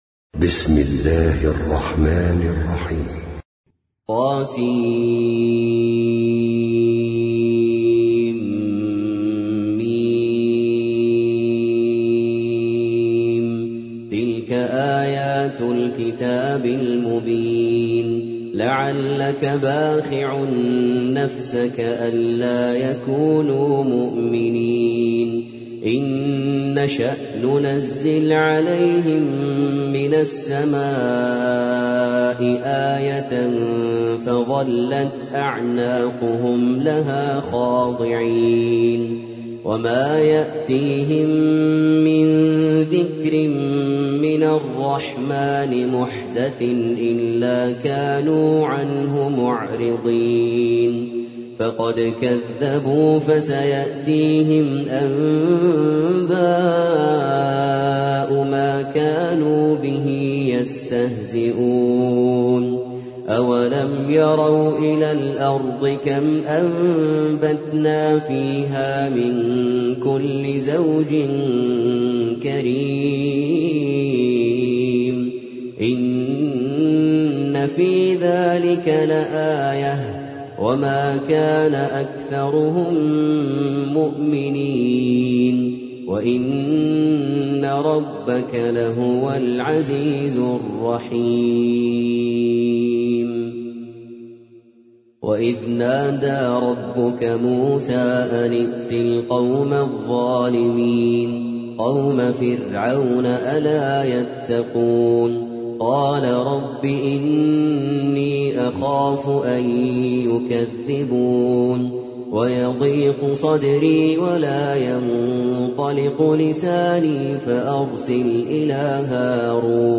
سورة الشعراء - المصحف المرتل (برواية حفص عن عاصم)
جودة عالية